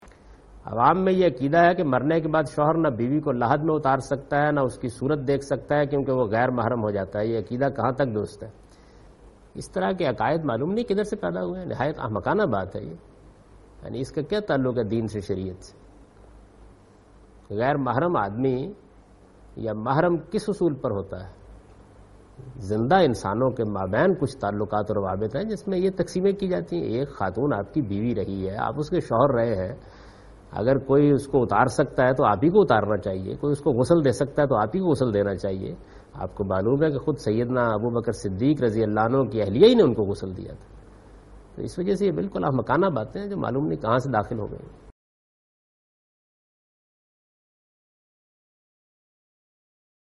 Javed Ahmad Ghamidi responds to the question' Does a man become Na-Mehram to his wife after she dies'?